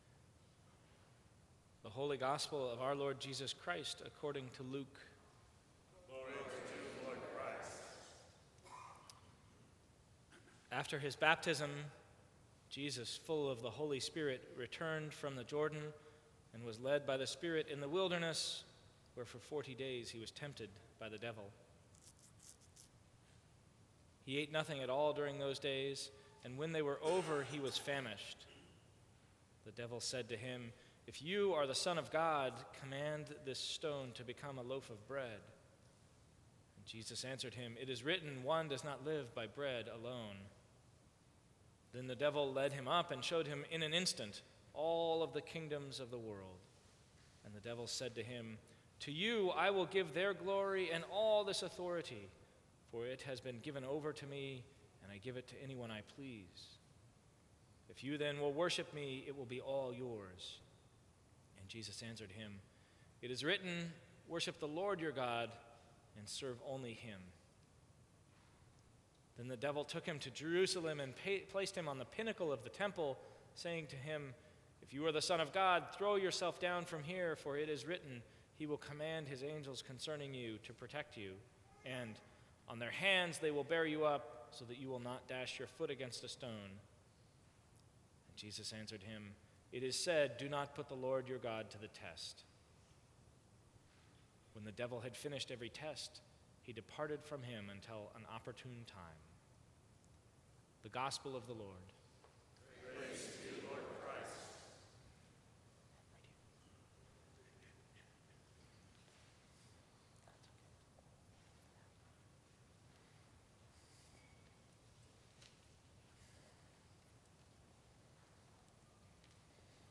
Sermons from St. Cross Episcopal Church Temptation, Trial…